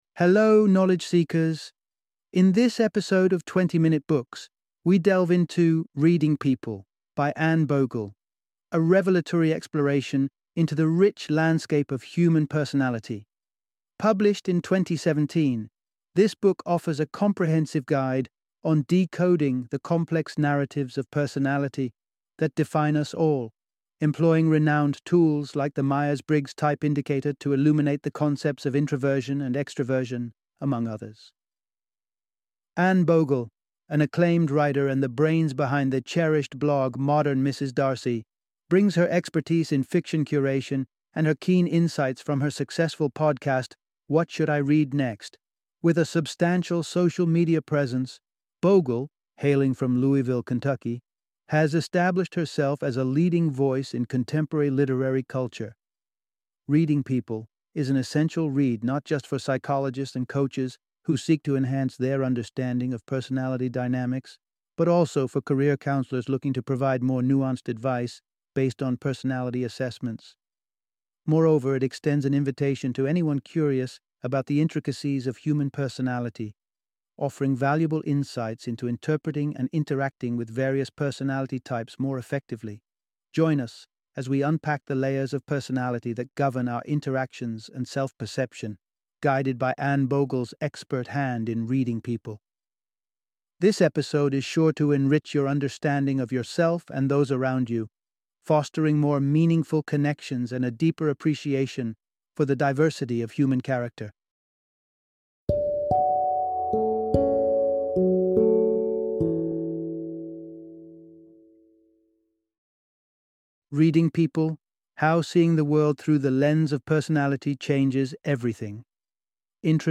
Reading People - Audiobook Summary